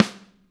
Snare 13.wav